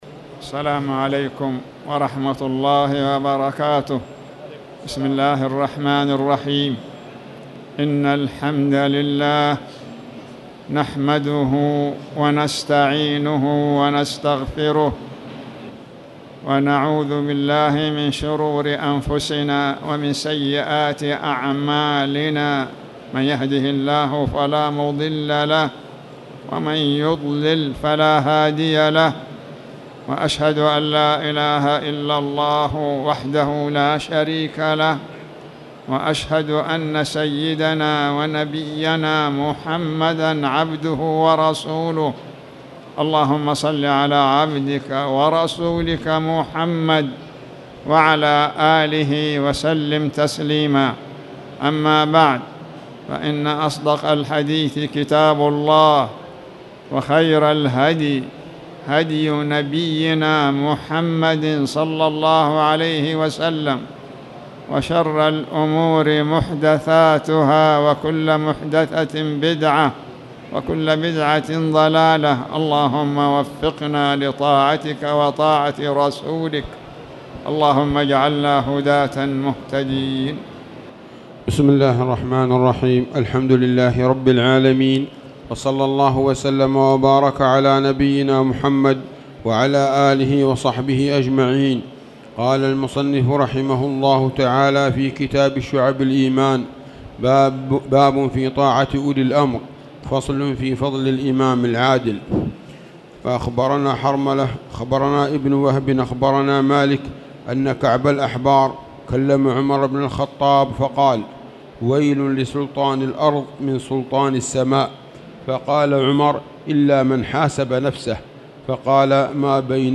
تاريخ النشر ٤ ذو القعدة ١٤٣٧ هـ المكان: المسجد الحرام الشيخ